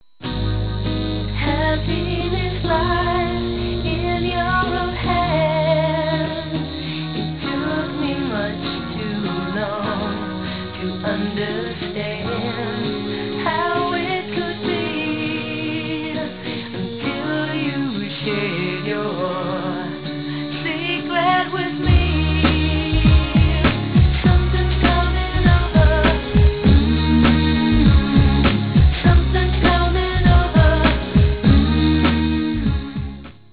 vocals
Synthesizers and Drum Programming, backing vocals
drums, keyboards
guitar
bass